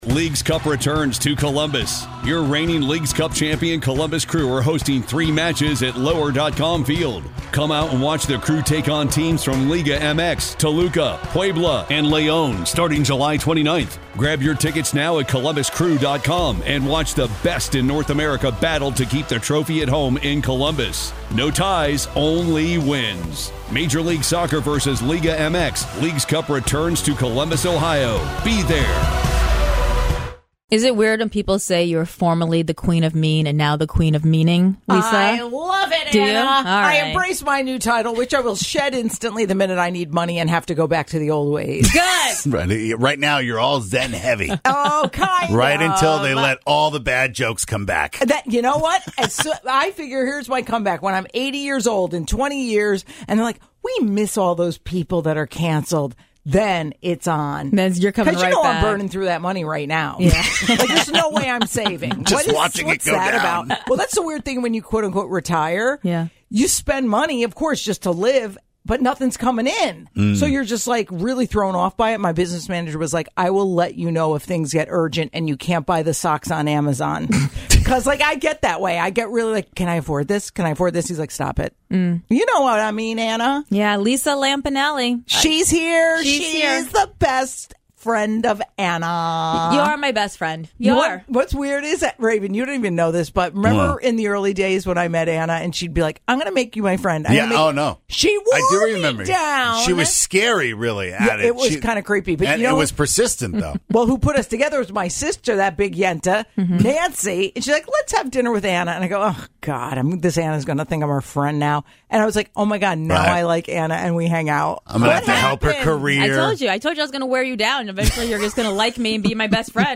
The Queen of Meaning In Studio